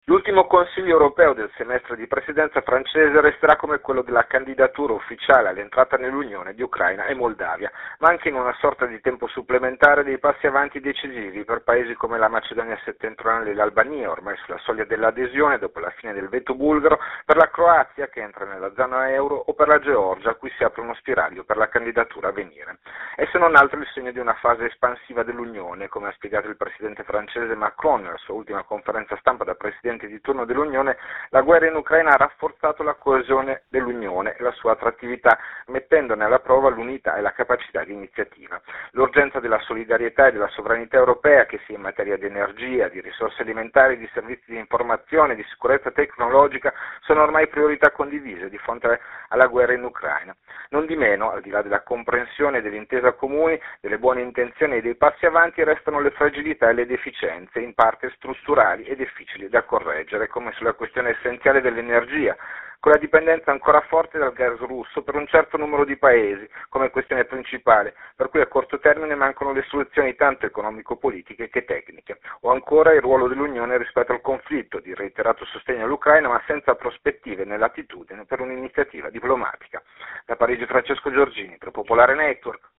Il Consiglio Europeo appena terminato è l’ultimo guidato dalla Francia, che a luglio passerà la presidenza di turno alla Repubblica Ceca. Sentiamo il servizio da Parigi